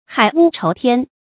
海屋筹添 hǎi wū chóu tiān
海屋筹添发音